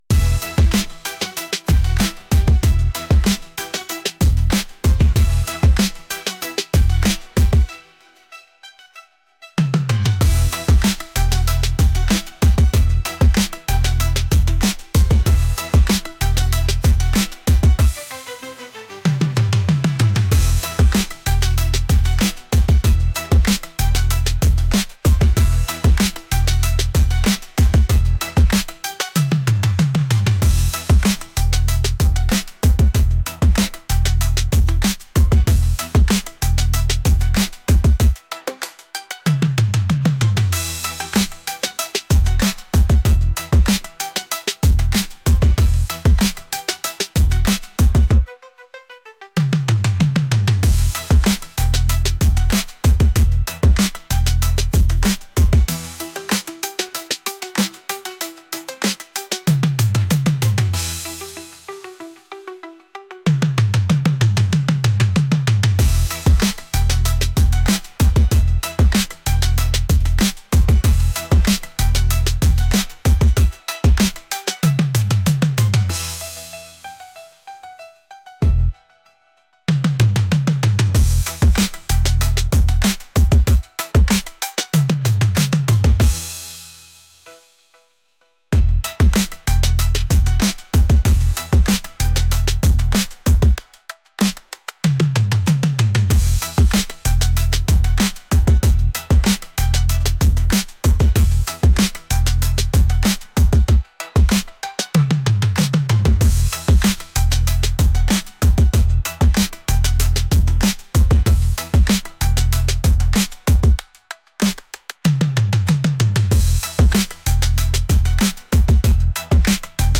energetic | rhythmic